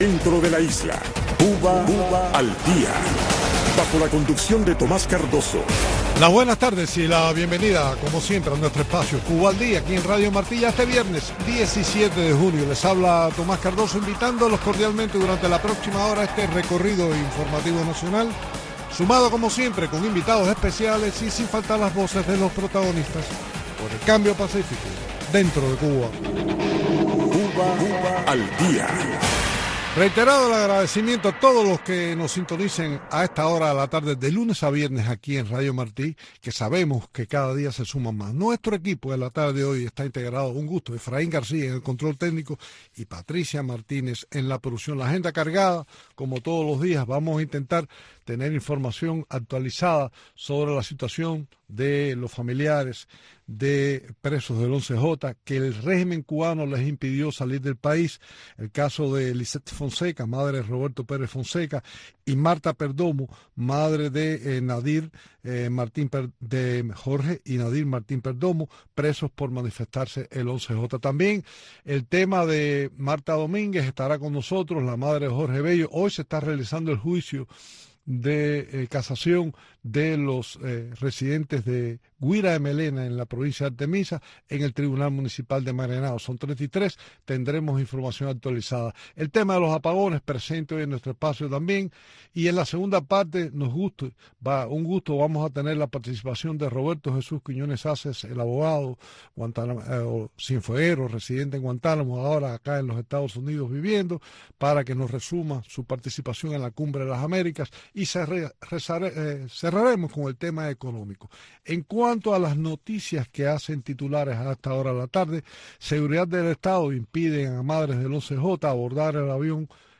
conecta cada día con sus invitados en la isla en este espacio informativo en vivo, que marca el paso al acontecer cubano.